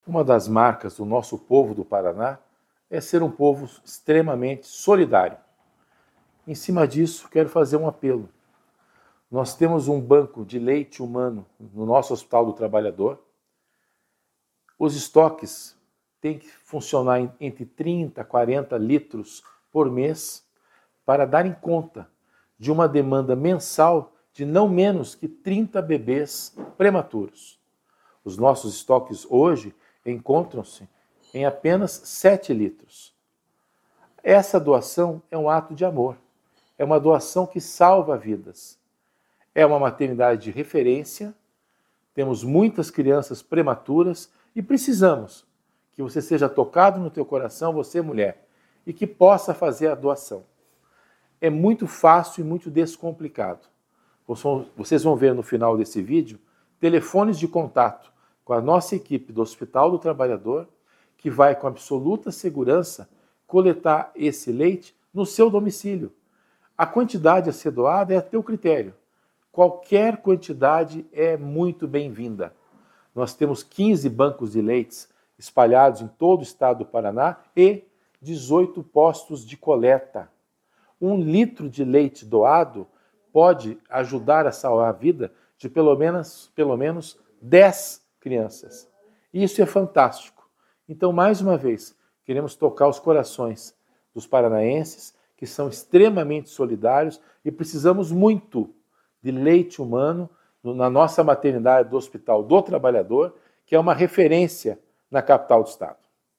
Sonora do diretor-geral da Secretaria da Saúde, César Neves, sobre a urgência por doações ao banco de leite do Hospital do Trabalhador